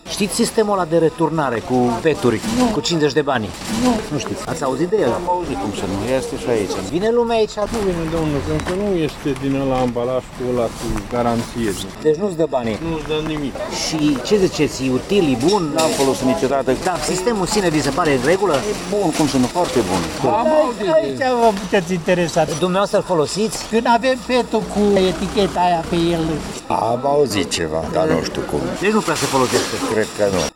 a surprins câteva păreri în parcarea unui hypermarket